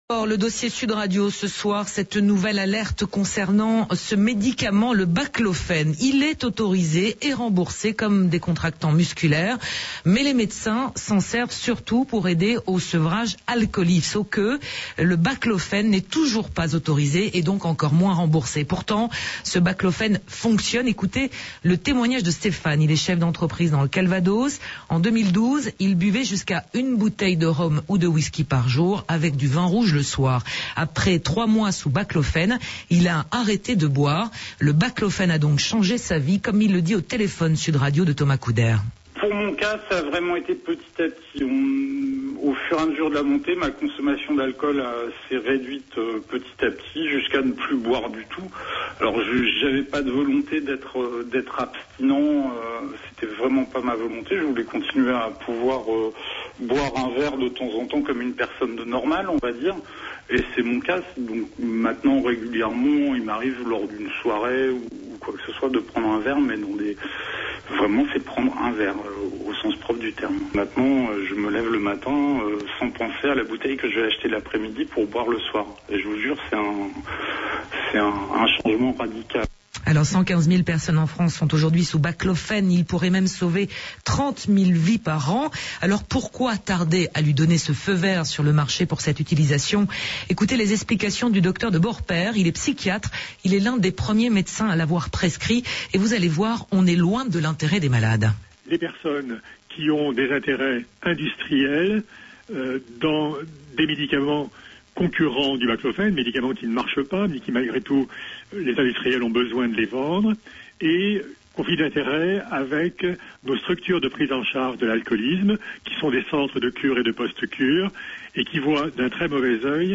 Le témoignage d’un patient